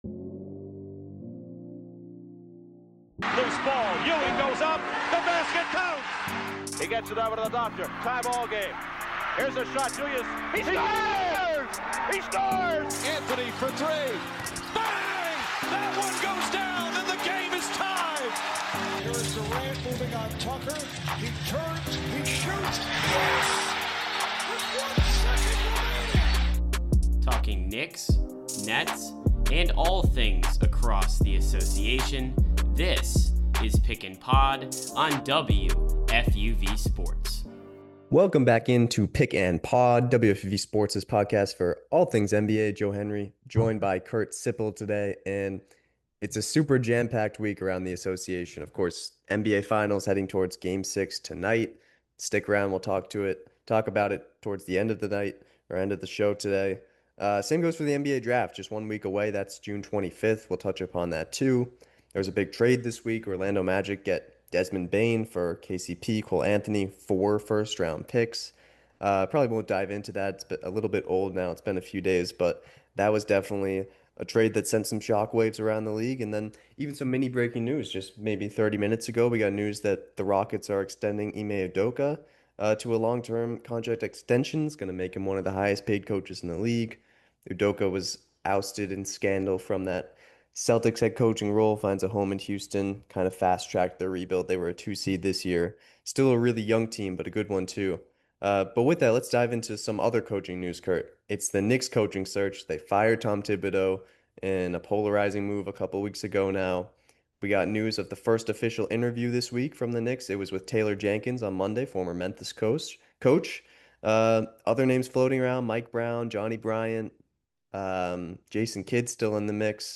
A weekly basketball podcast from the WFUV Sports team featuring news, commentary, analysis and more.